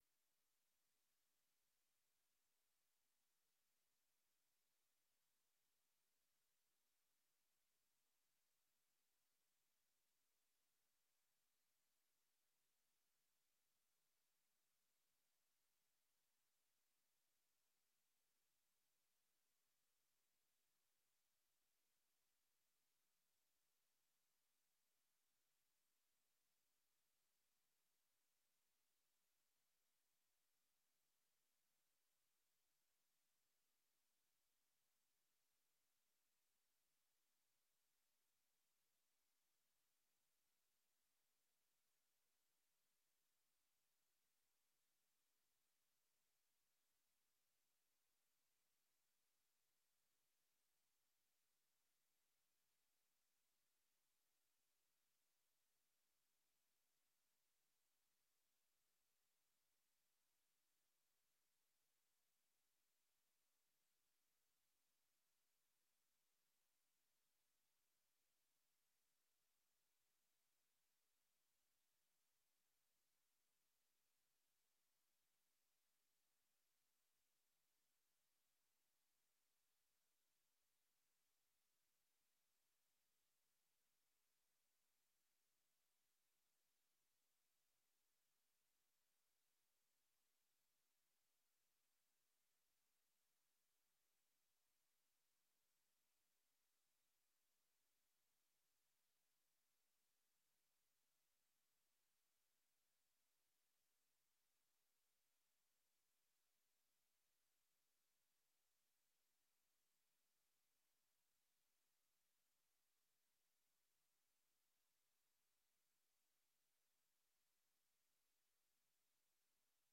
Buitengewone Raadsvergadering (Installatie burgemeester)
Locatie: Raadzaal